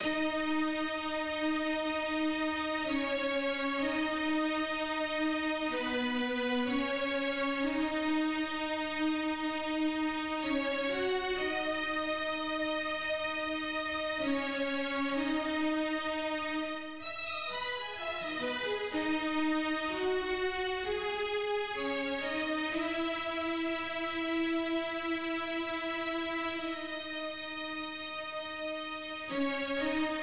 14 strings D.wav